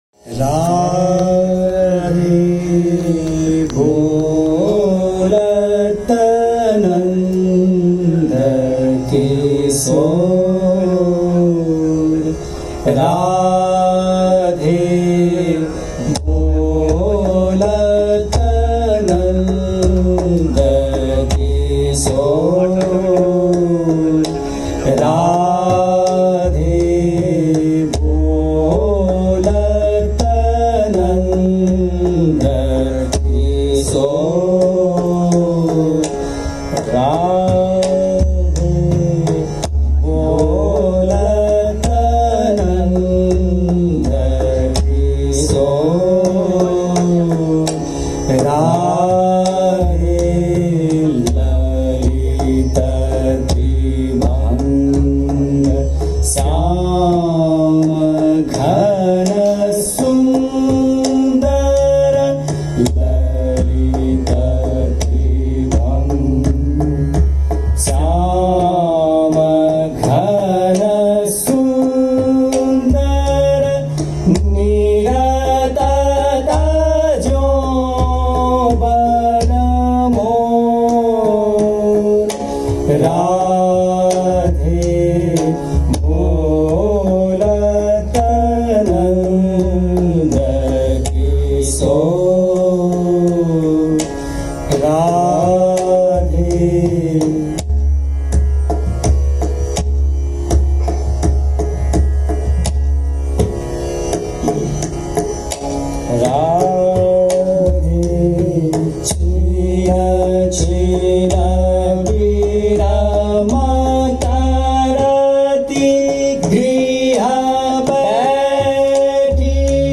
దాగర్ వాణీ శైలికి చెందిన గాయకులు.
వారు ఆలపించిన సూరదాస్ కృతిని ఇక్కడ వినండి: